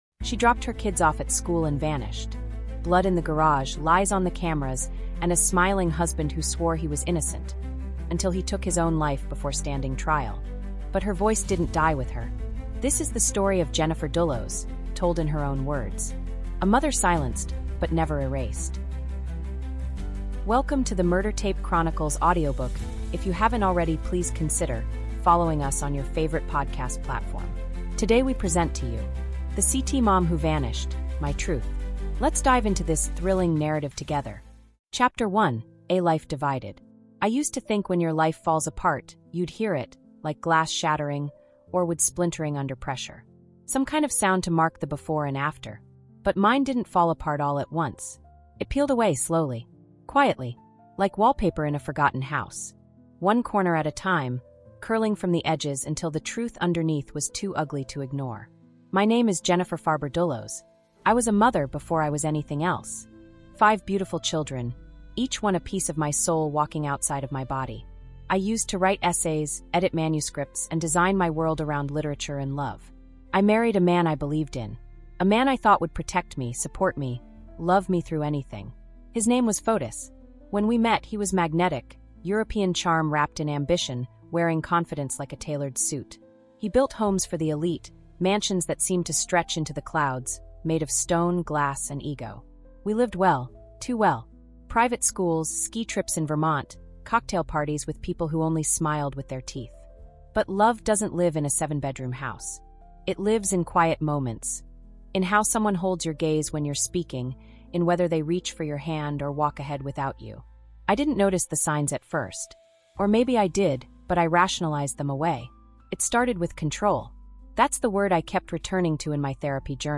The CT Mom Who Vanished: My Truth | Audiobook
This is the voice of a woman the world refused to forget.